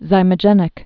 (zīmə-jĕnĭk) also zy·mog·e·nous (zī-mŏjə-nəs)